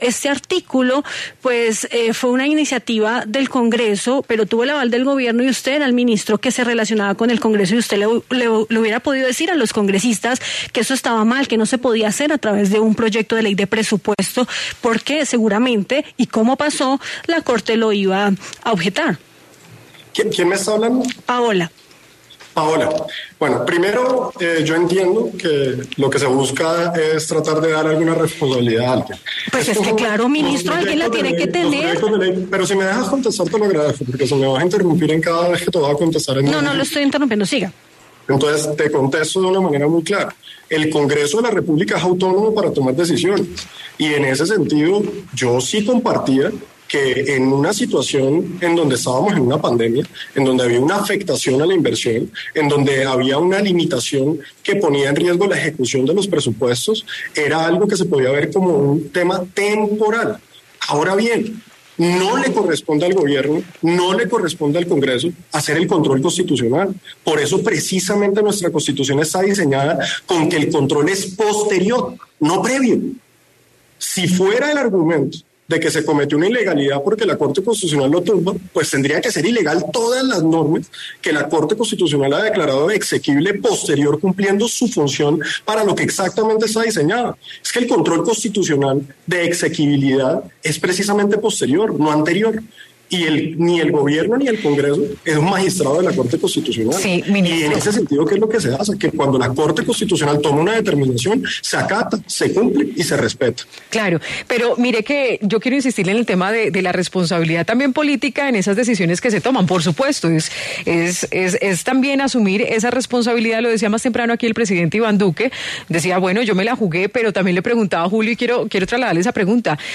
El ministro del Interior, Daniel Palacios, conversó en Sigue La W sobre la polémica reforma de la Ley de Garantías que tumbó la Corte Constitucional.
A continuación, escuche la entrevista con Daniel Palacios, el ministro de Interior.